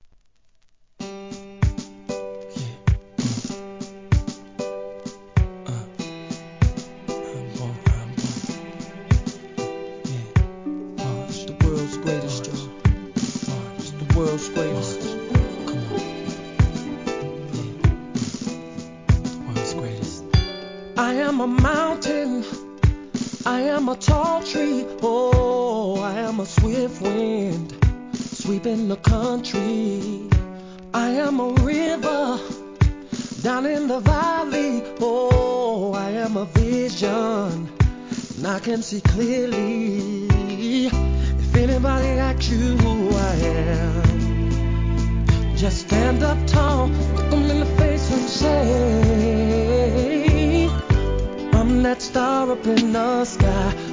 HIP HOP/R&B
タイトル通りSLOWなR&Bを5曲!!